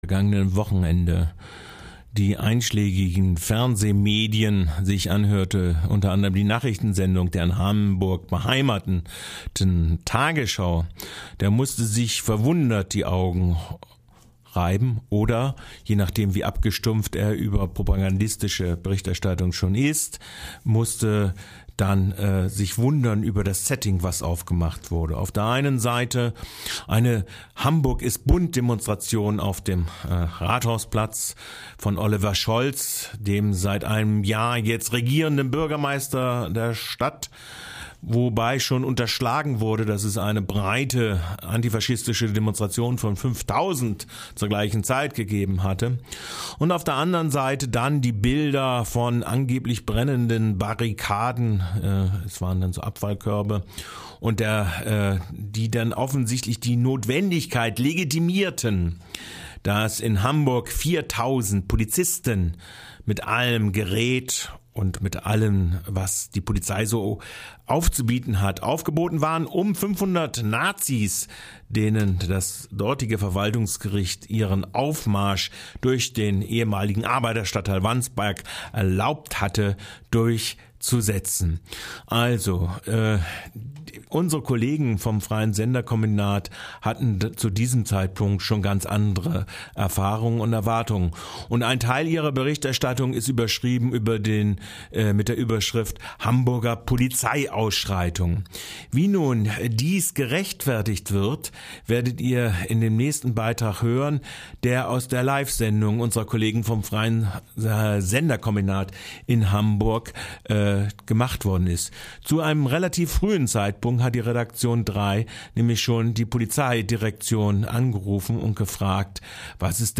Ausschreitungen?? Bloss von wem ? Wie ein Polizeisprecher sich verabschiedet...